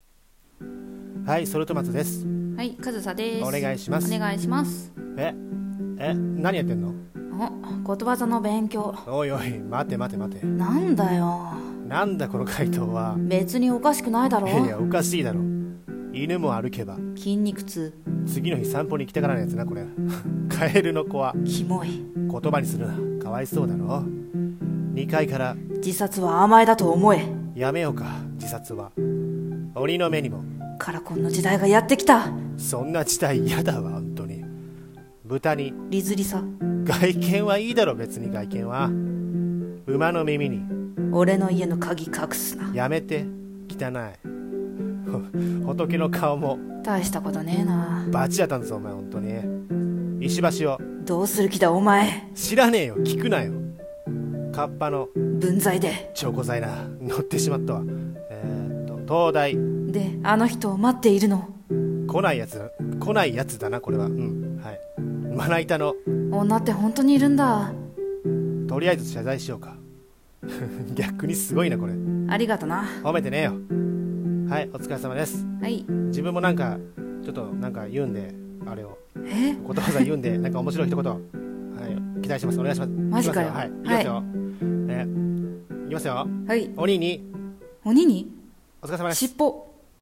ギャグ声劇「ことわざ」｢掛け合い｣